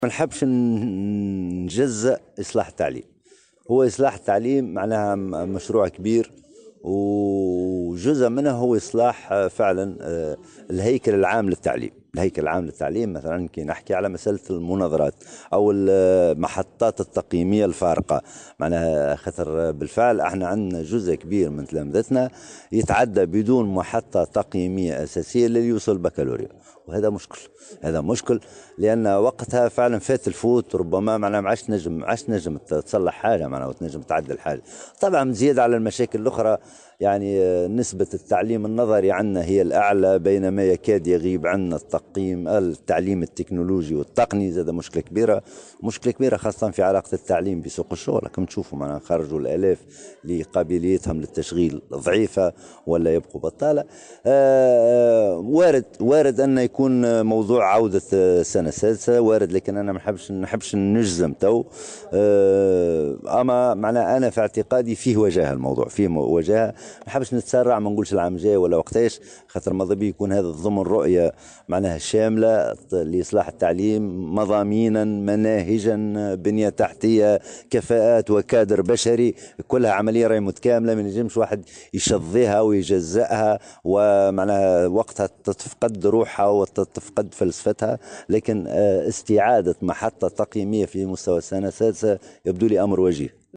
قال وزير التربية، محمد الحامدي اليوم على هامش إشرافه على انطلاق مناظرة "السيزيام"، إن العودة مجدّدا لإجبارية هذه المناظرة يبقى واردا.